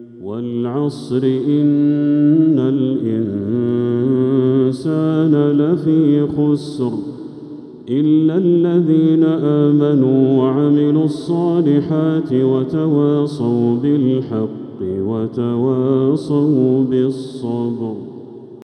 سورة العصر كاملة | صفر 1447هـ > السور المكتملة للشيخ بدر التركي من الحرم المكي 🕋 > السور المكتملة 🕋 > المزيد - تلاوات الحرمين